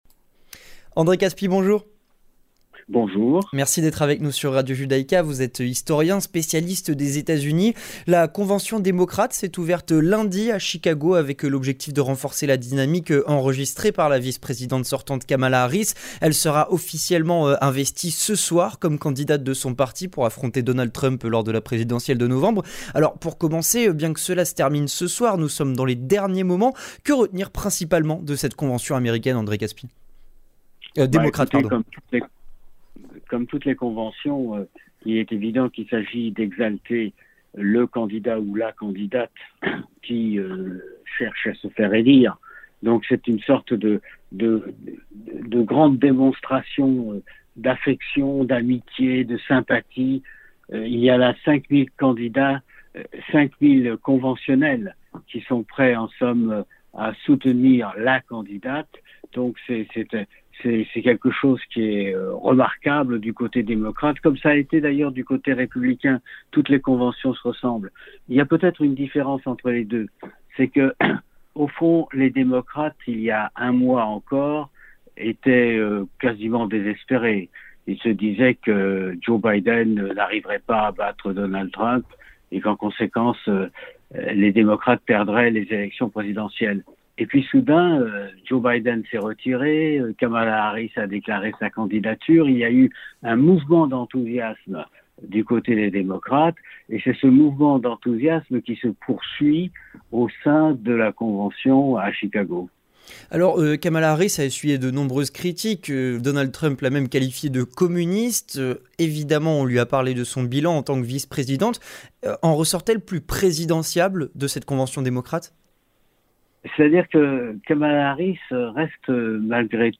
Avec André Kaspi, historien, spécialiste des Etats-Unis